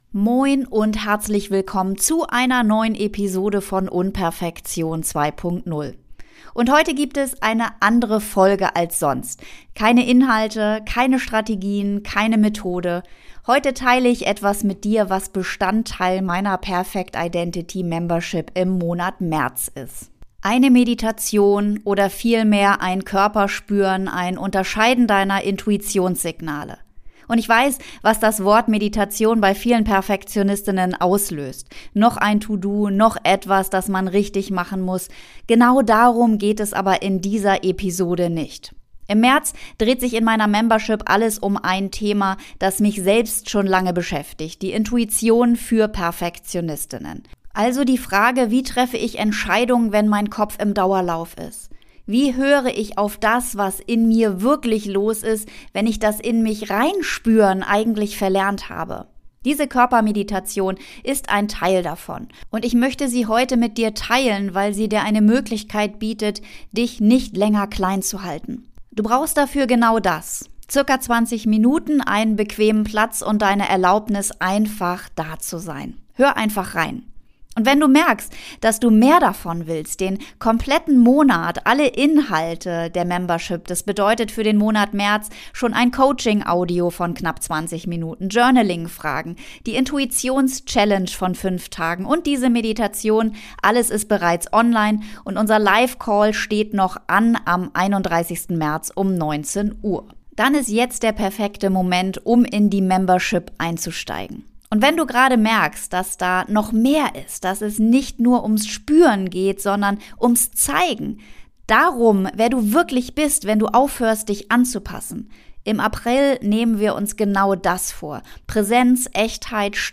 In dieser Episode nehme ich dich mit in eine geführte Körper-Meditation, die ich ursprünglich exklusiv für meine Membership aufgenommen habe.